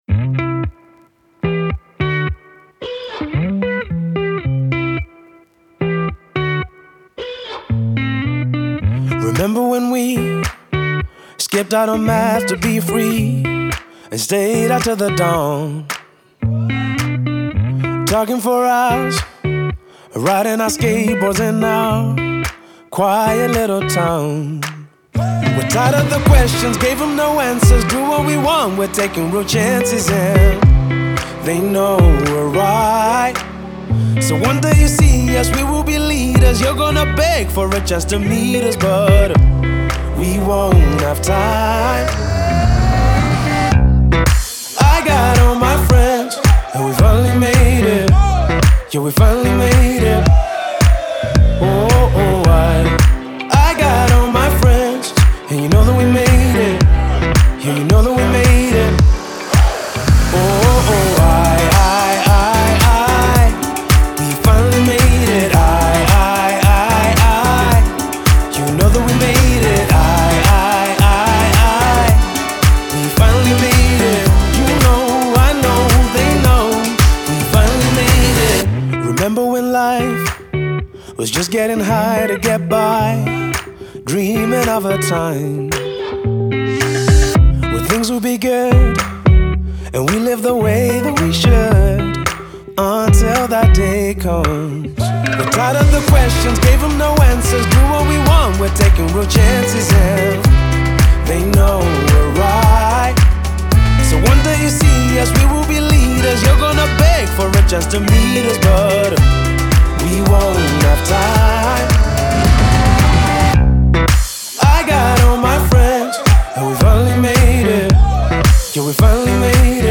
душевная поп-баллада